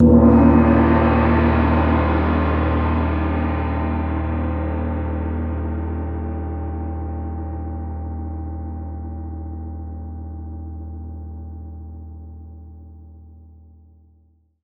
Index of /90_sSampleCDs/Partition E/MIXED GONGS